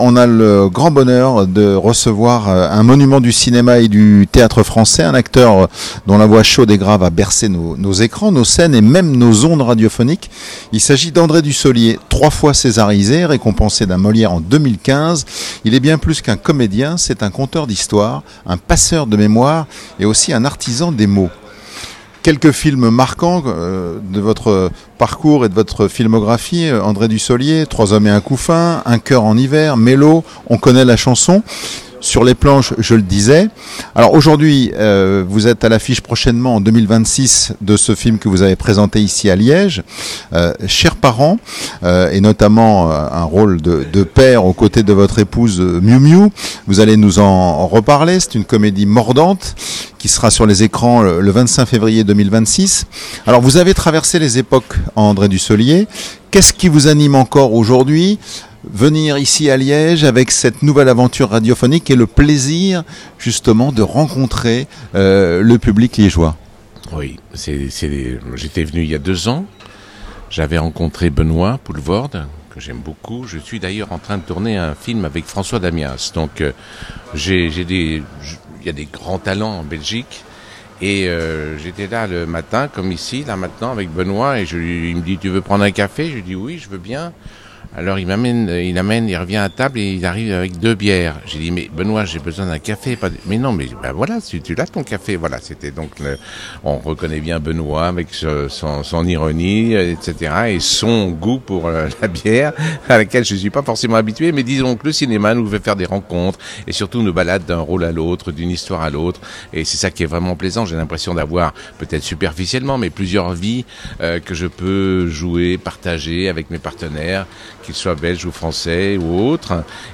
Une rencontre à la fois drôle, mélodieuse et touchante, à l’image de ce comédien qu’on ne présente plus. Trois Césars, un Molière, et toujours cette voix capable de transformer une phrase en souvenir.
Dussolier transforme chaque silence en réplique, chaque mot en émotion.